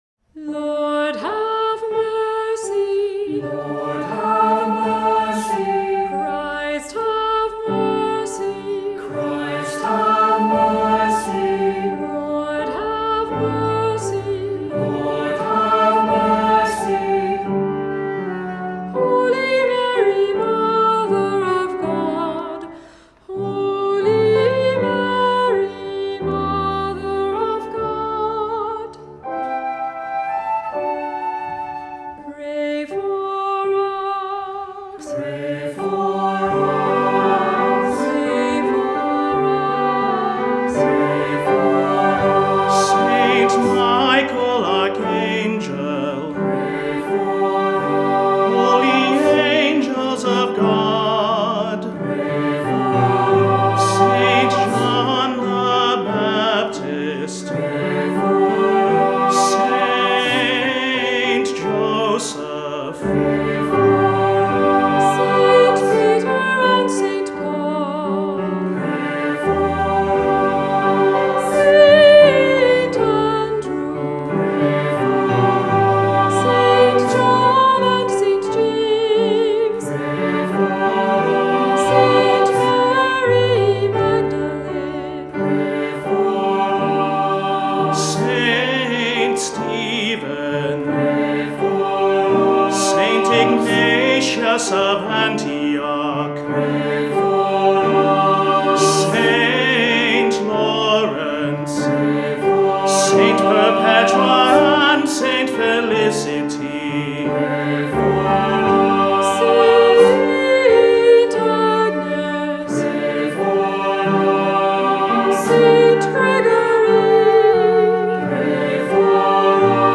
Voicing: Assembly,Cantor,Unison